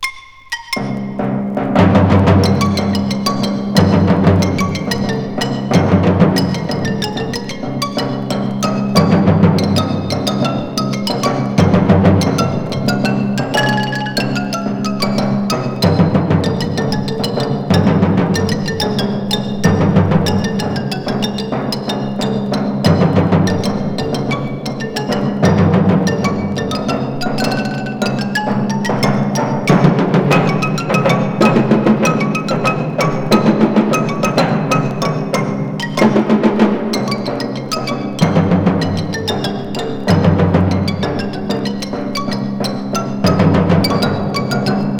Classical, Contemporary　France　12inchレコード　33rpm　Stereo